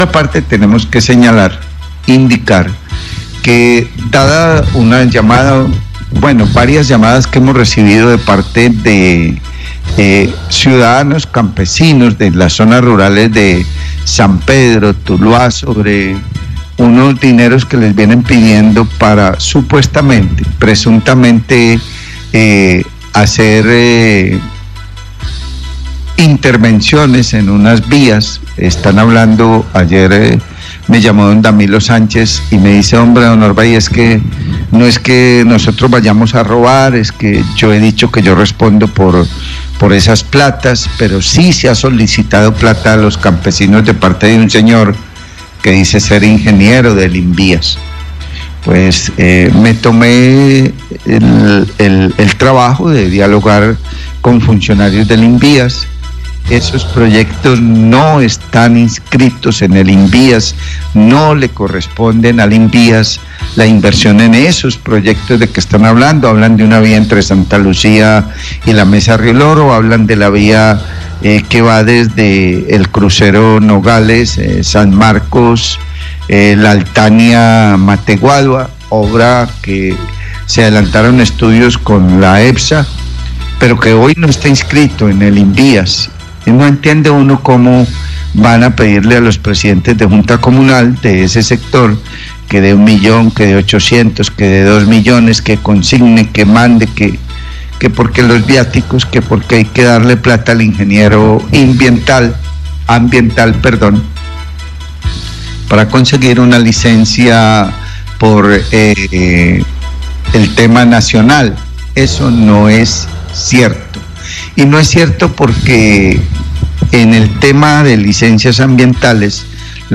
Radio
En la nota el periodista nombra a la Epsa pero la denuncia no tiene que ver con la empresa.